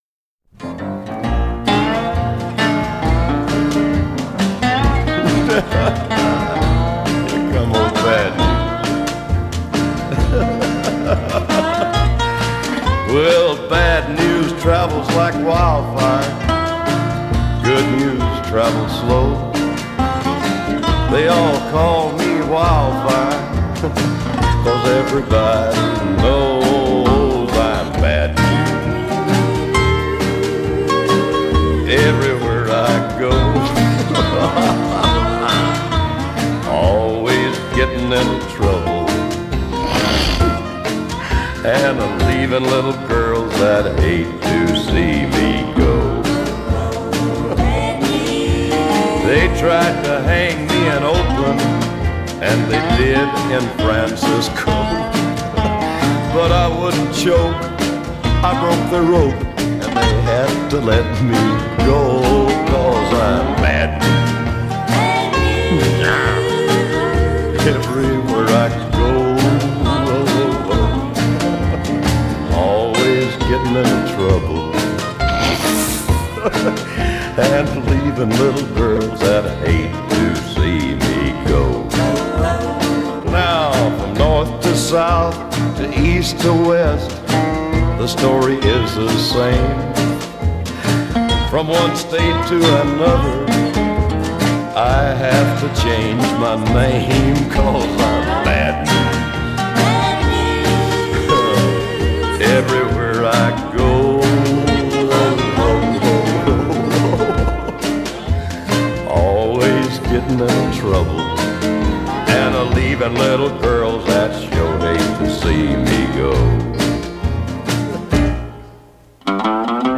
کانتری country